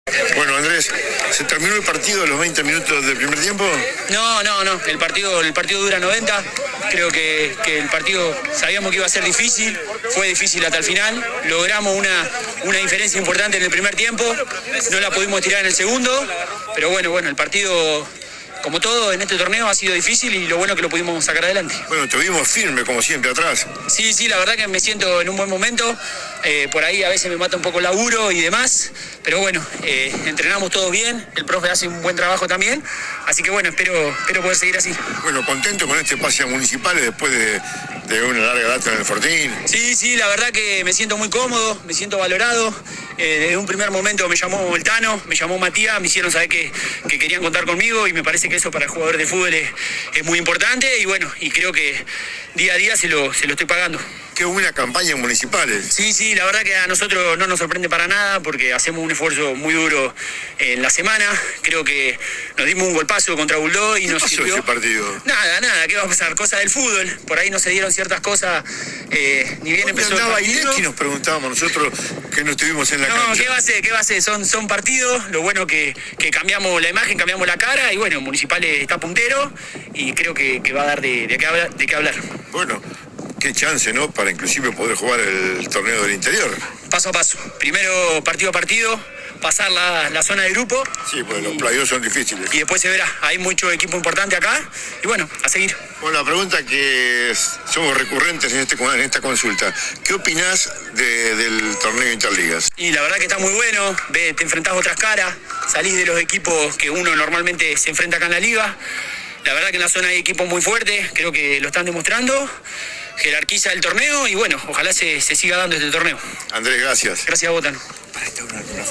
AUDIOS DE LAS ENTREVISTAS